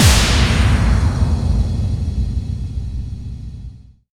VEC3 FX Reverbkicks 22.wav